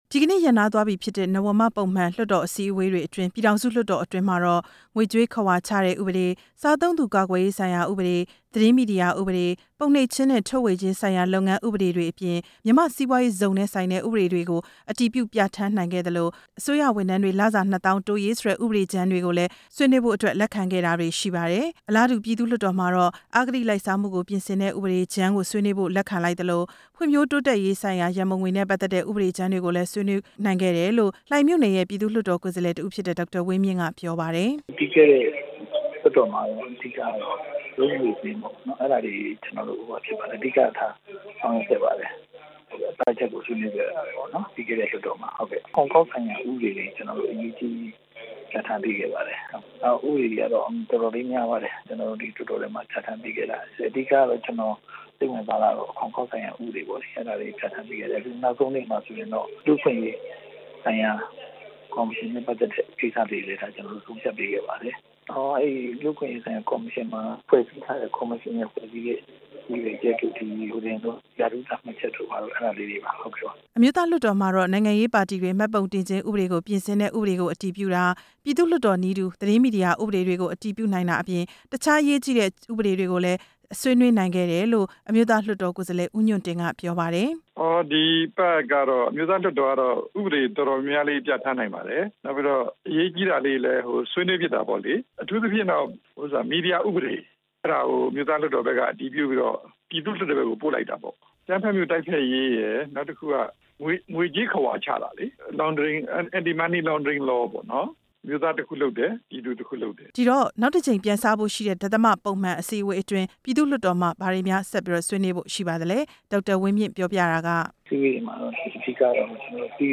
နေပြည်တော် ဇမ္ဗူသီရိခန်းမဆောင်မှာ ဒီကနေ့ ကျင်းပတဲ့ ပြည်ထောင်စုလွှတ်တော် နာယက သူရဦးရွှေမန်းနဲ့ ပြည်သူ့လွှတ်တော် တရားဥပဒေ စိုးမိုးရေးနဲ့ တည်ငြိမ်အေးချမ်းရေးကော်မတီ ဥက္ကဌ ဒေါ်အောင်ဆန်းစုကြည်တို့ ပူးတွဲပြုလုပ်တဲ့ သတင်းစာရှင်းလင်းပွဲမှာ အခုလို ပြောကြားခဲ့တာပါ။